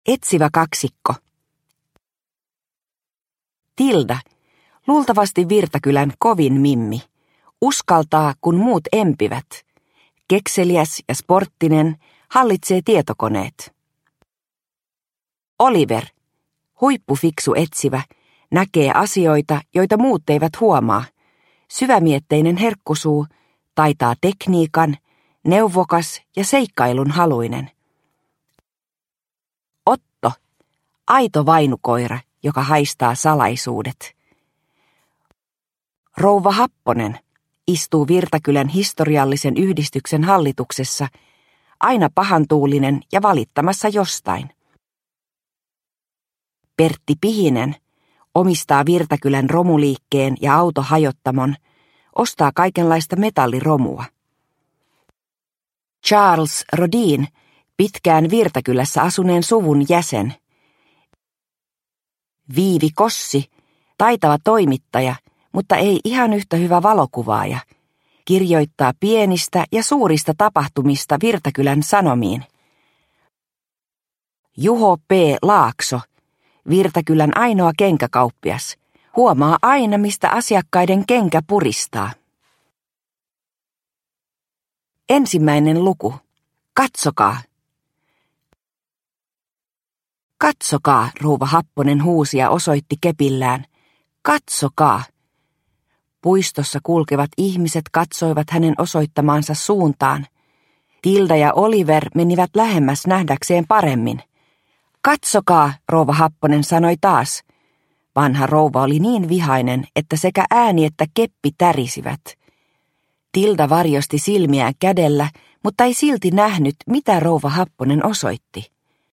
Operaatio pronssipatsas – Ljudbok – Laddas ner